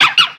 LILLIPUP.ogg